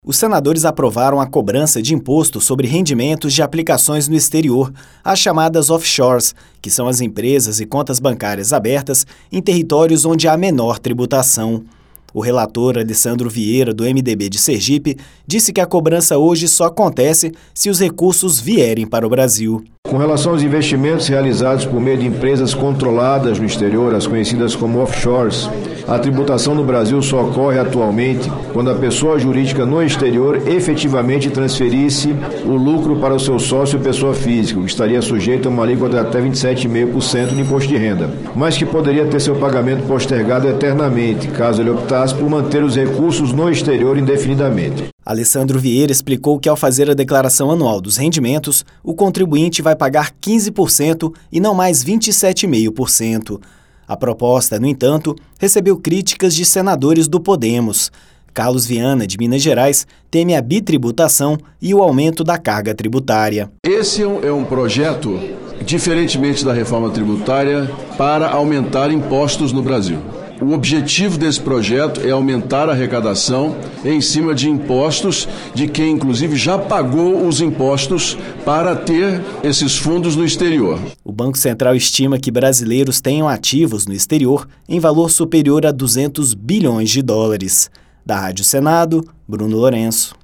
O relator, Alessandro Vieira (MDB-SE), explicou que alíquota será de 15%. O senador Carlos Viana (Podemos-MG) disse temer, no entanto, a bitributação e o aumento da carga tributária.